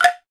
Index of /90_sSampleCDs/NorthStar - Global Instruments VOL-2/PRC_Angklungs/PRC_Angklungs